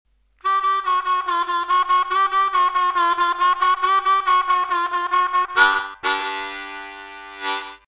Two blasts on the straight draw. Two blasts on the half bend. Two blasts on the the full bend. And back again.